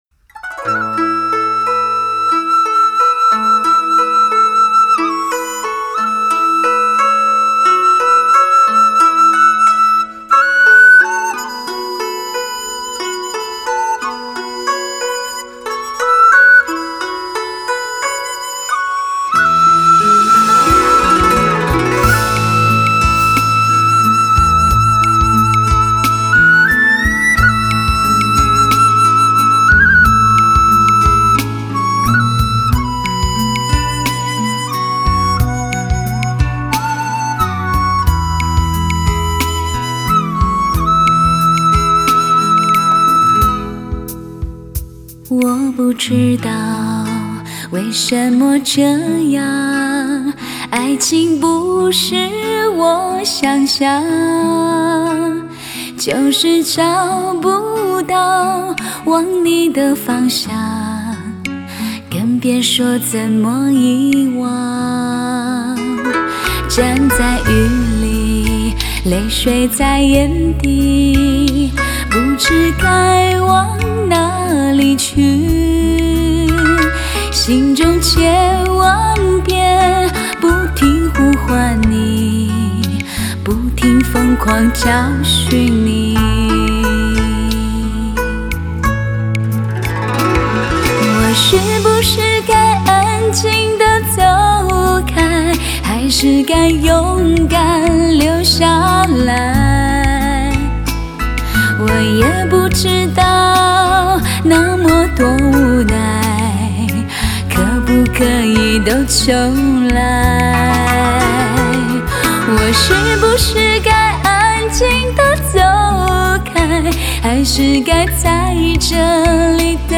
Жанр: Cinese Pop